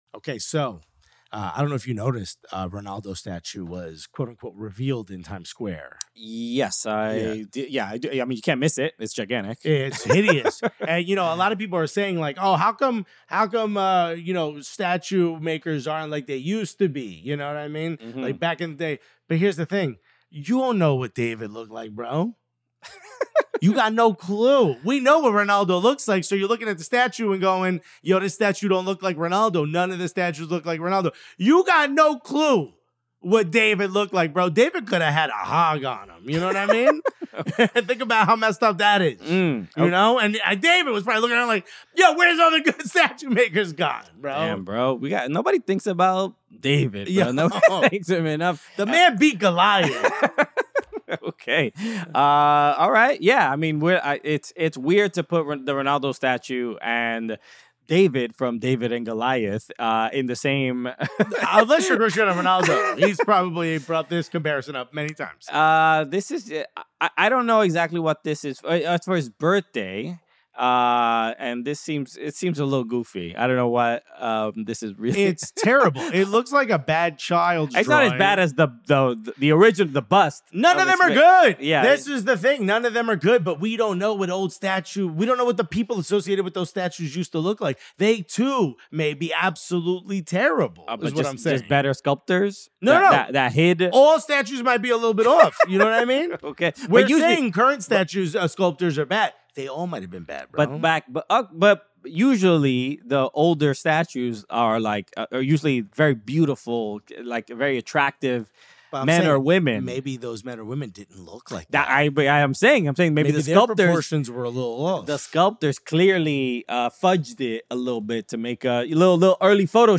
Rooster and the Villain: An American Soccer Podcast Interview Series